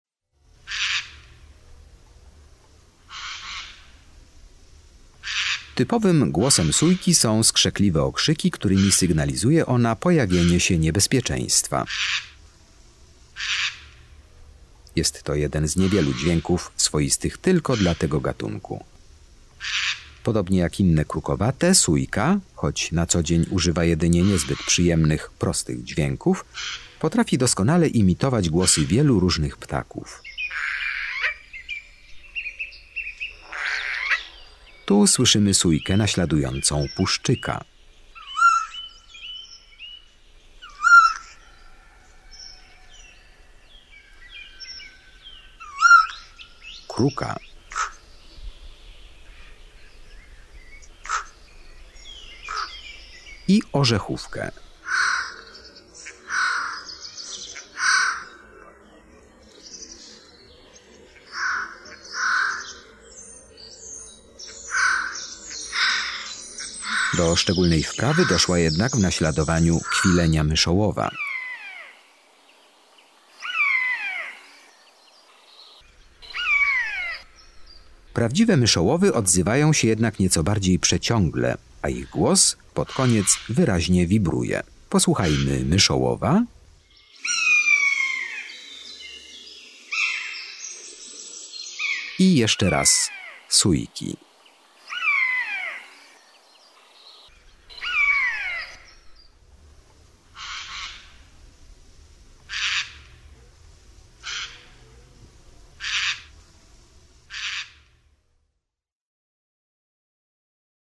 14 SÓJKA.mp3